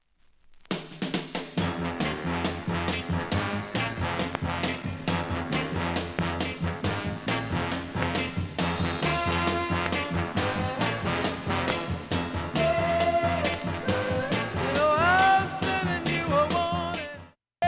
盤面きれいで音質良好全曲試聴済み
A-3始めにかすかなプツが６回出ます。
◆ＵＳＡ盤オリジナル Stereo
高揚感あるフィリーソウル
ハッピーなシャッフル・ソウル